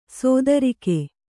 ♪ sōdarike